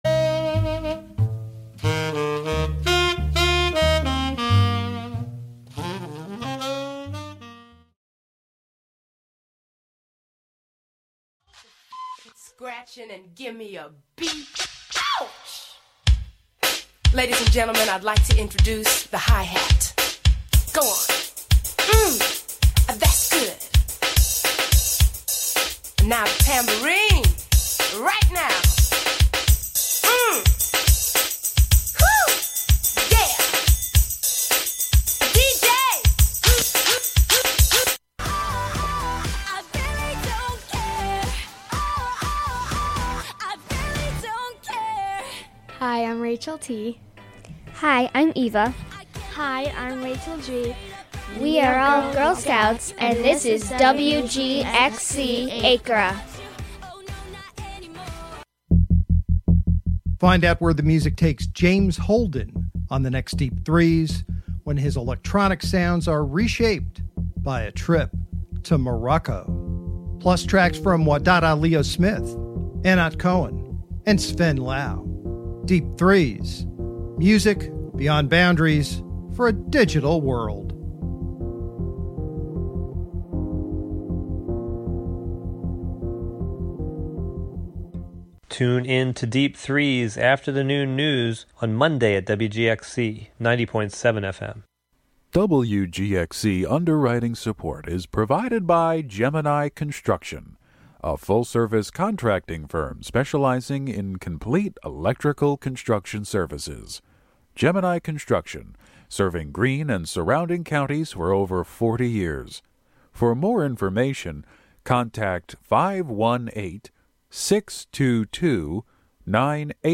The Irish Show features the best of Irish music, sports results, requests, and interviews. Featuring one of the largest collections of records in the USA from Ireland, many of which were never offered for sale in America.